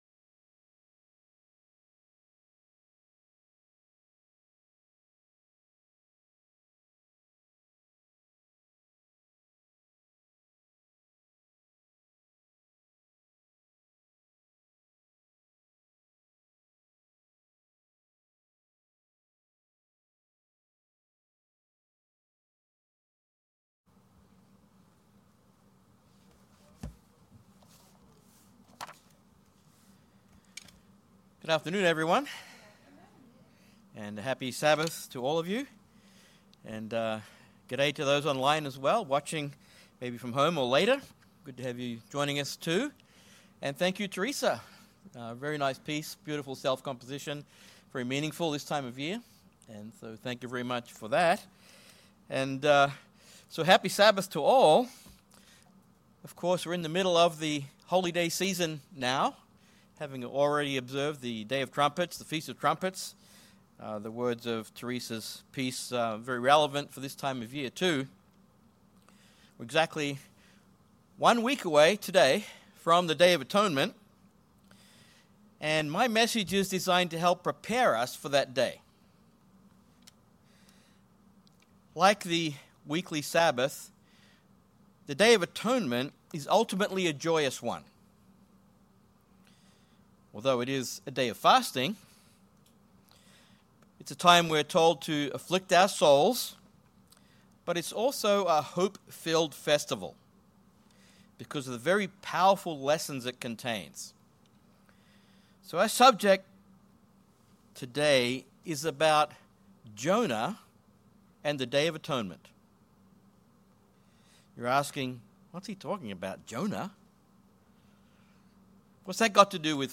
In this sermon you will see the important connections between Jonah and this Holy Day.